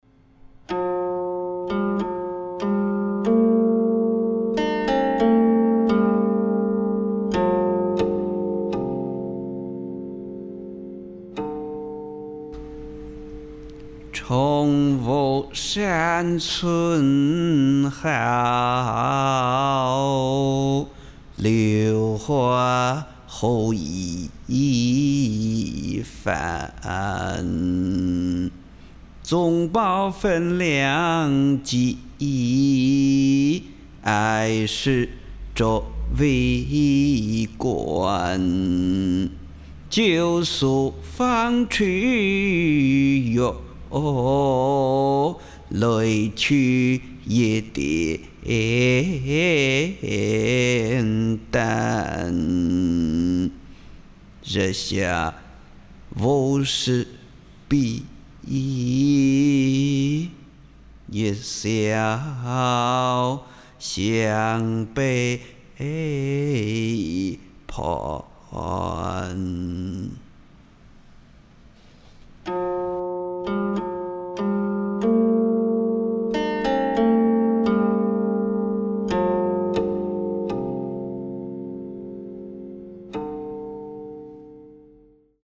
吟哦